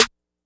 Snare.aiff